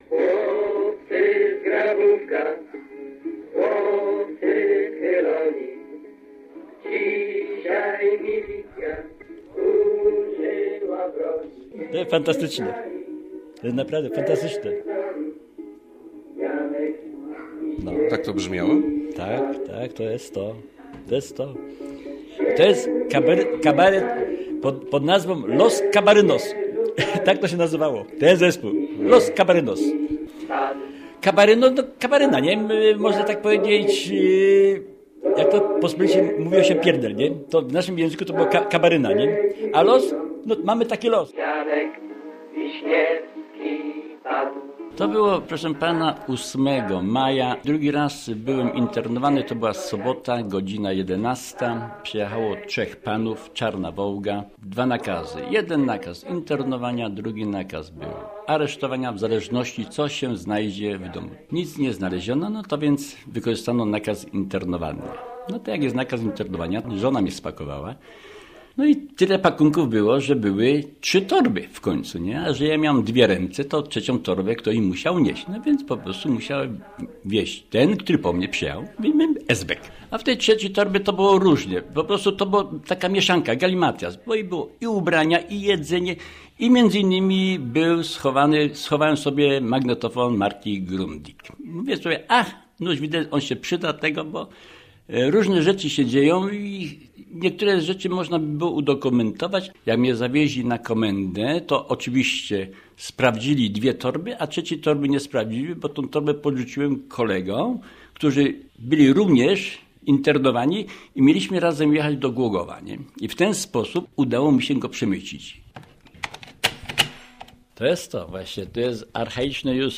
Los Kabarynos - reportaż